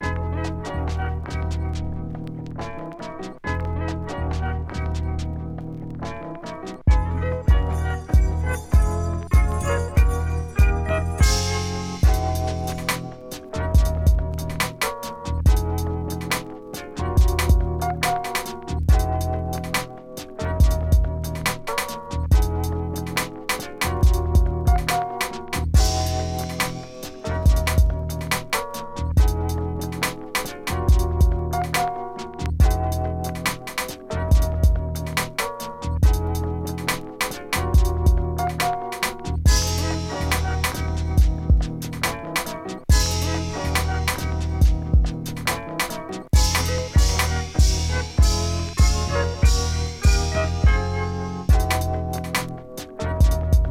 Electro Funk味あるバンシートラック